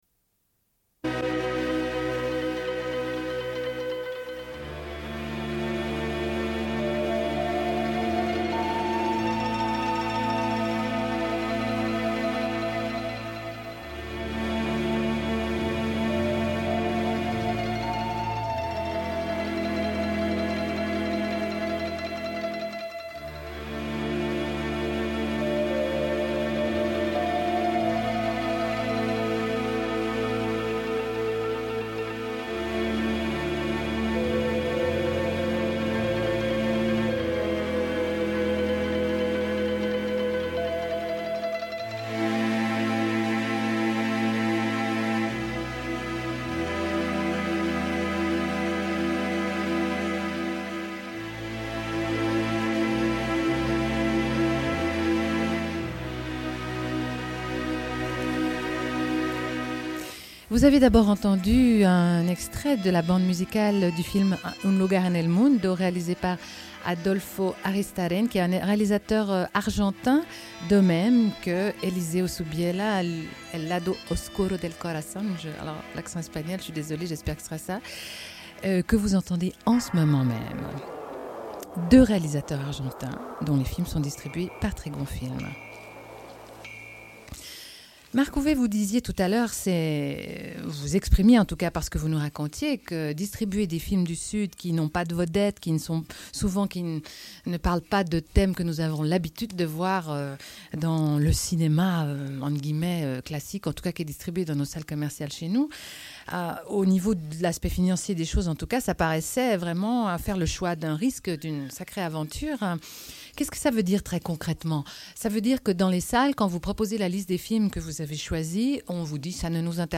Une cassette audio, face B28:42
Sommaire de l'émission : en direct